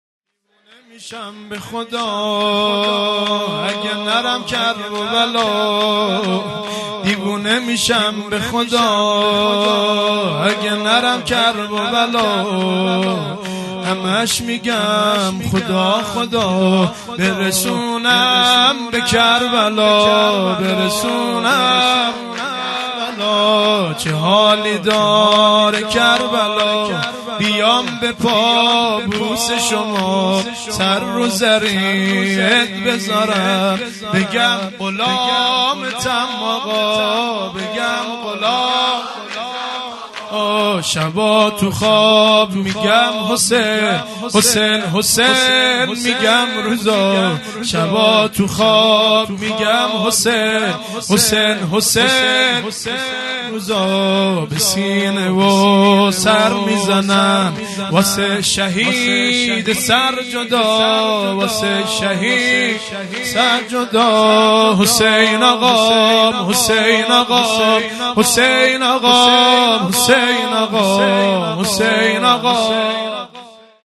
هیئت مکتب الزهرا(س)دارالعباده یزد - شور | دیونه میشم بخدا اگه نرم کرببلا مداح